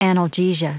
Prononciation du mot analgesia en anglais (fichier audio)
analgesia.wav